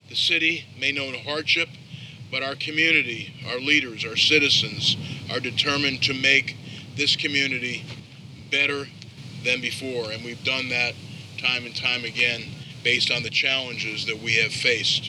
City Manager Jeff Pomeranz says they will continue the recovery effort.